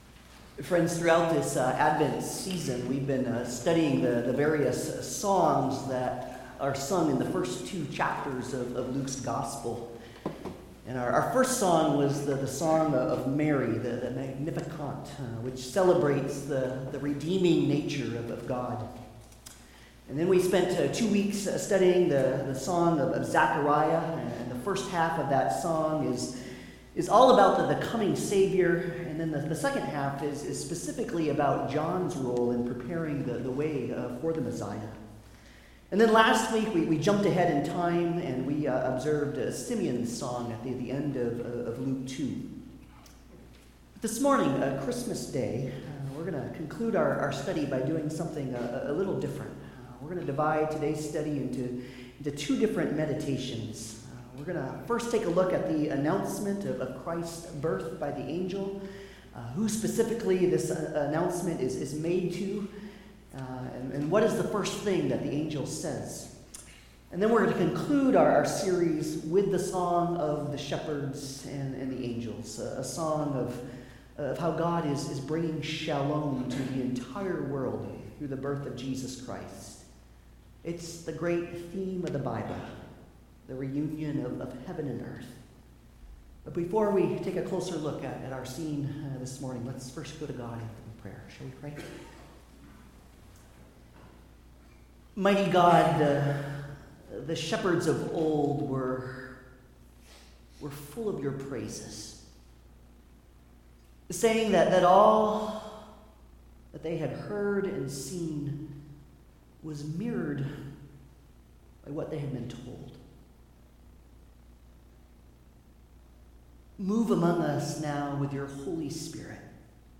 Passage: Luke 2:8-20 Service Type: Holiday Service